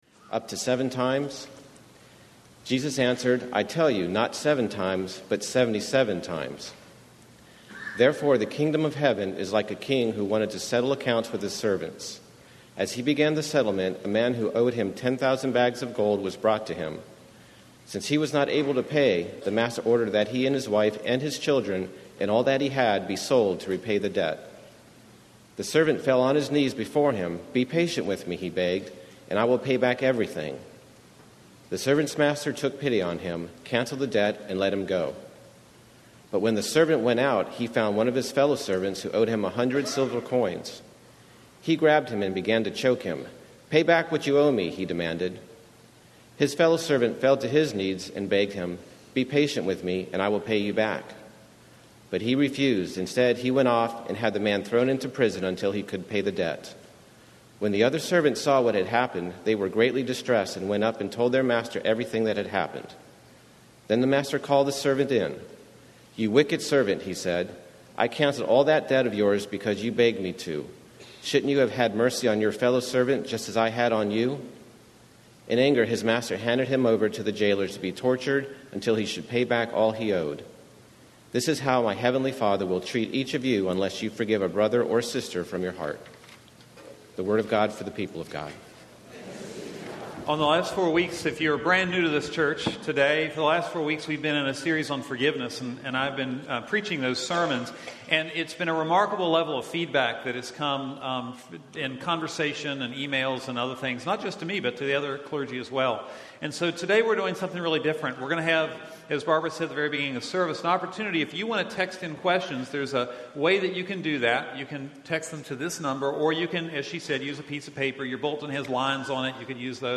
Forgiveness: A Panel Discussion on Forgiveness - Floris United Methodist Church